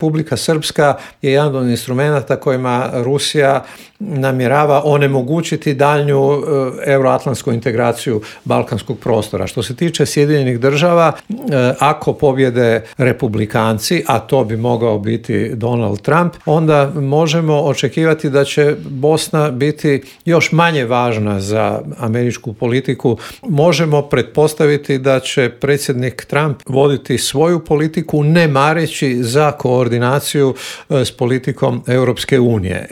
Zašto su izbori na Tajvanu prijetnja svjetskom miru i zašto su izgledi za okončanja rata u Ukrajini ove godine mali, pitanja su na koje smo odgovore tražili u intervuju Media servisa s analitičarom Božom Kovačevićem.